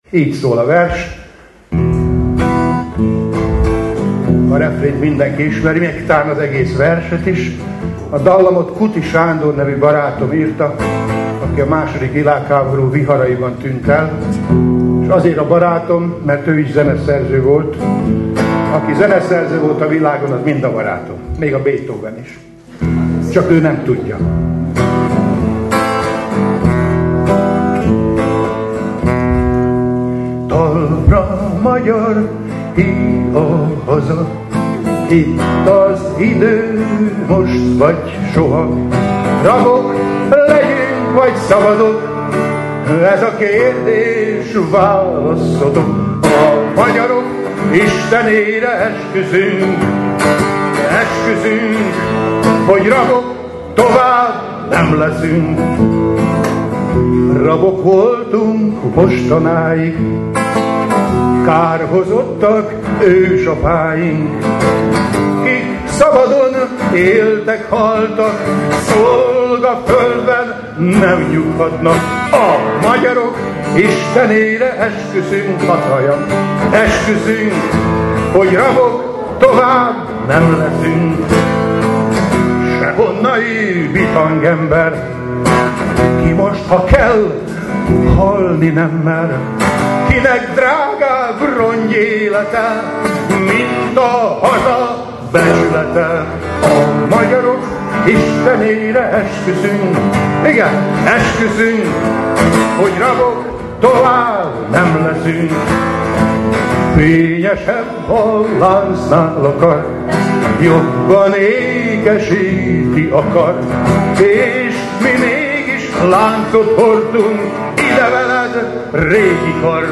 És, természetesen, a közönség ismét együtt énekelt vele.